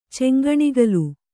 ♪ ceŋgaṇigalu